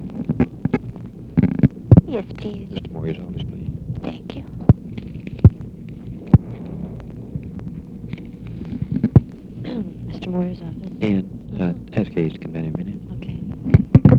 Conversation with BILL MOYERS
Secret White House Tapes